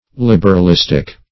Liberalistic \Lib`er*al*is"tic\ (-[i^]s"t[i^]k), a.